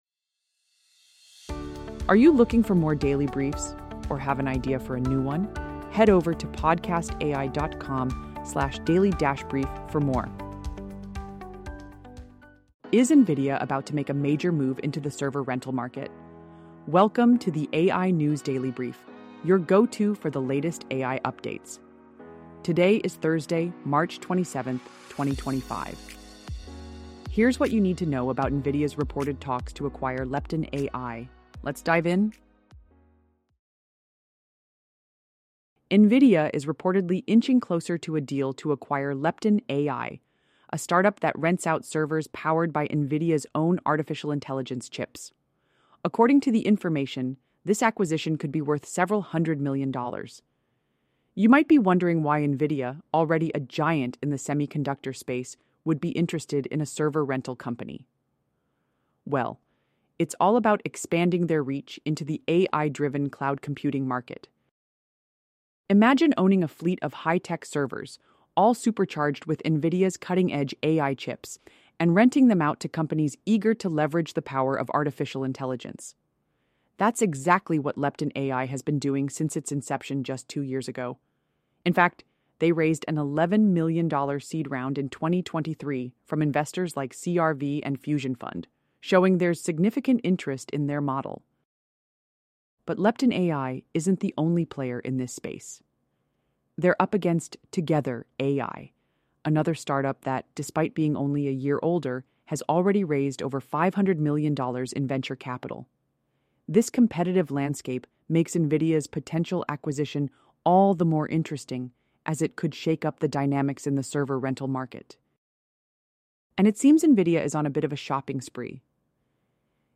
A daily news update on the latest in artificial intelligence, covering advancements in AI technology, industry partnerships, and key players shaping the future of AI.